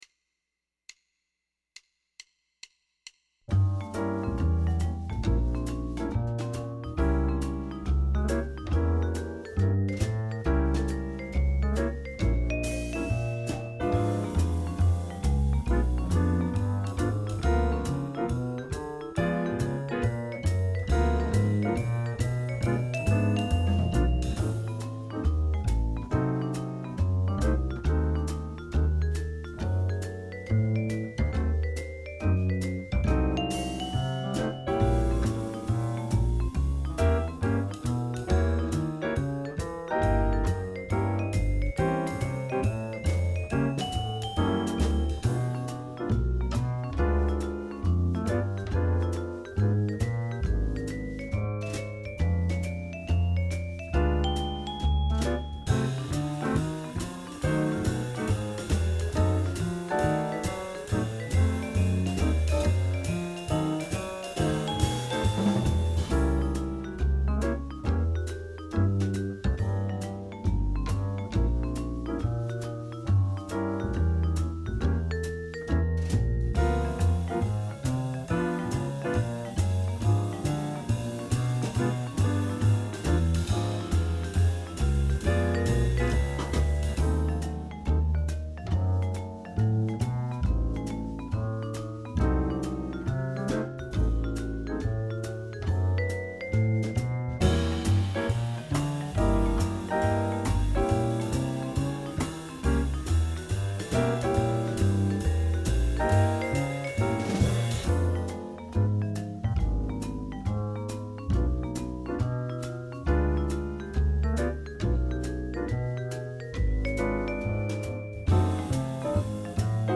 Below is a chart and a recording of that paraphrased Sonny Stitt lick in all 12 keys, in case you want to further develop your diminished scale chops.
blue-lights-stitt-dim-lick-from-pow.mp3